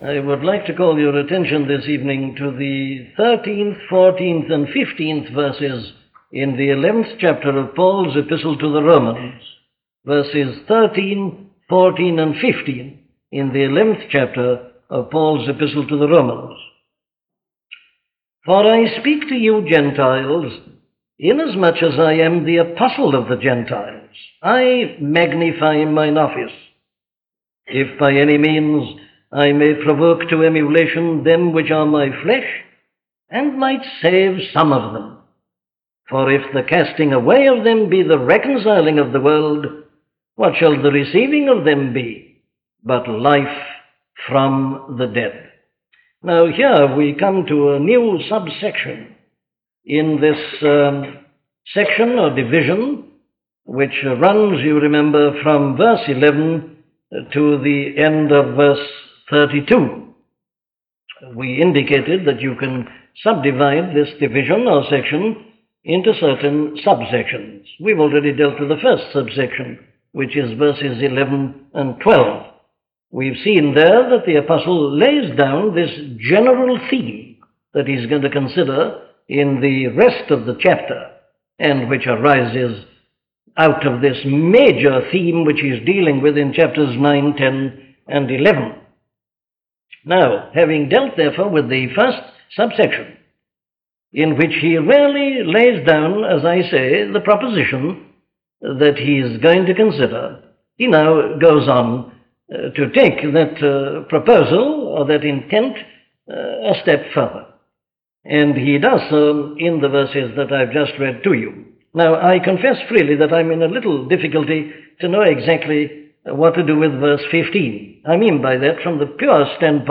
Free Sermon | Book of Romans | Chapter 11 | Page 1 of 2
An audio library of the sermons of Dr. Martyn Lloyd-Jones.